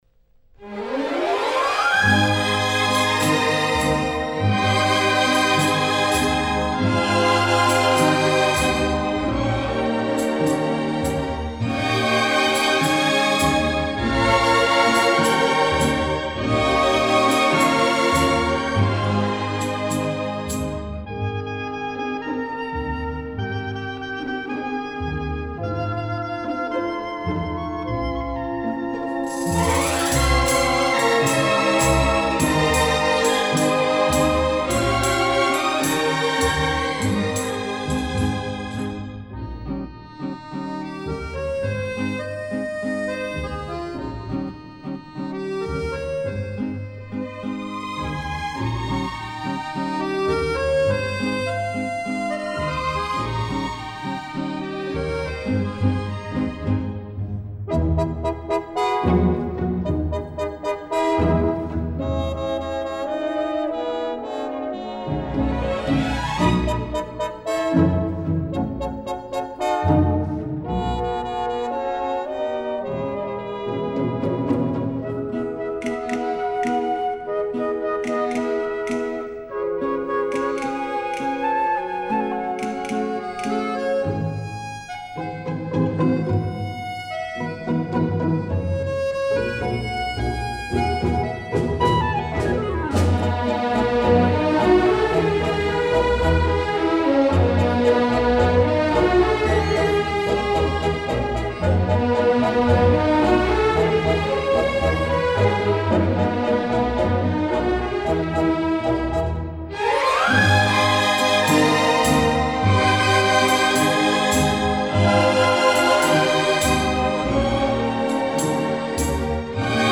Genre:Easy Listening,Instrumental